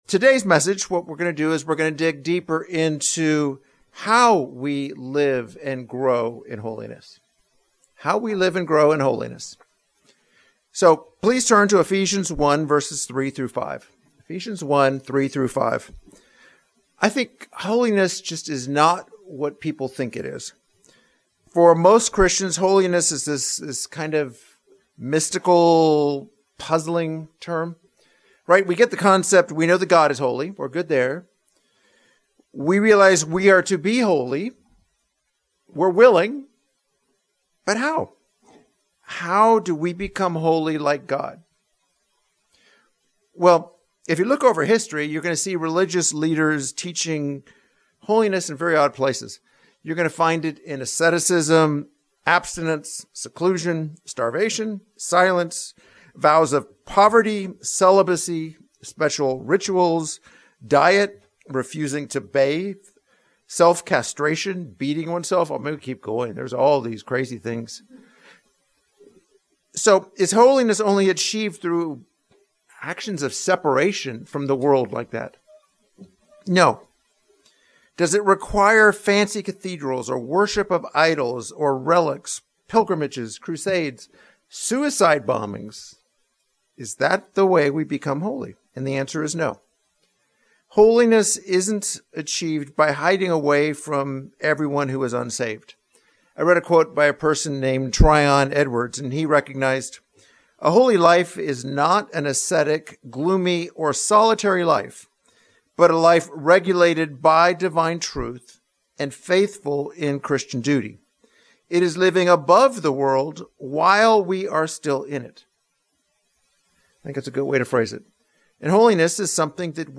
This second sermon on holiness focuses on how we become holy like God.